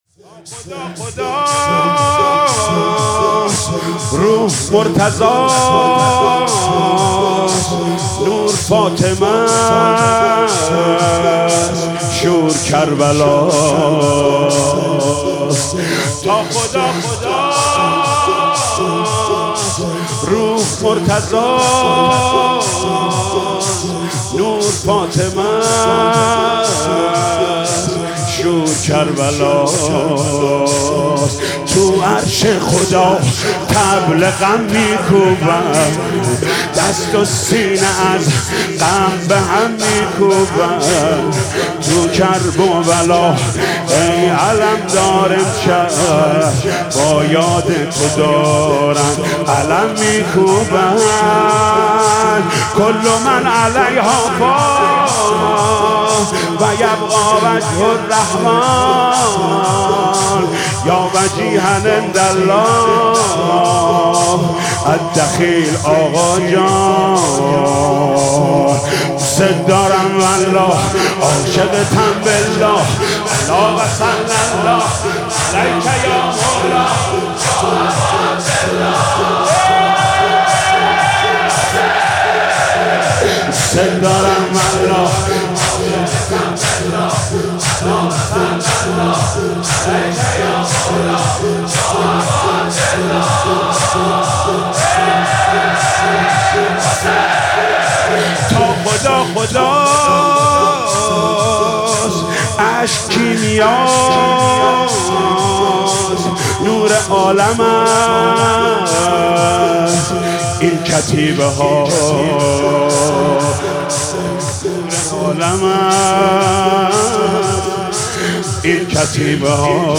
مراسم عزاداری شب اوّل محرم ۱۴۰۳ با صدای محمود کریمی
بخش سوم - زمینه (ای صلابت حیدر، ثارالله)